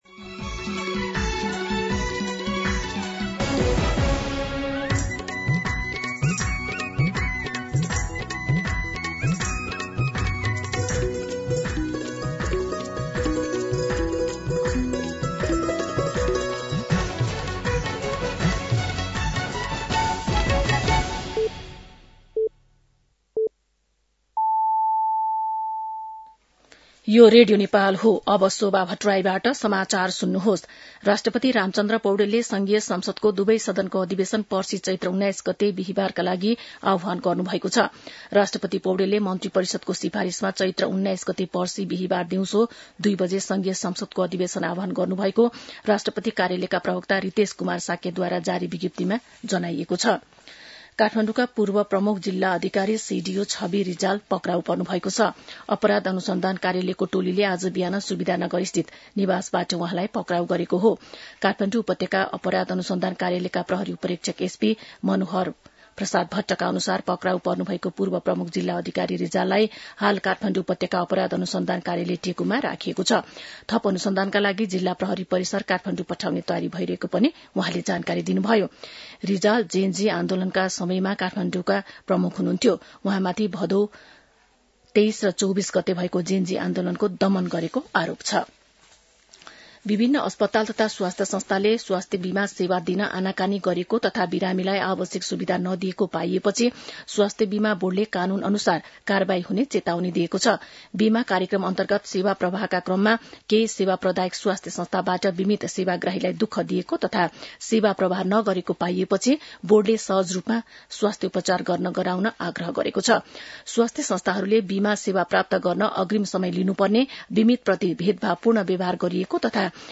मध्यान्ह १२ बजेको नेपाली समाचार : १७ चैत , २०८२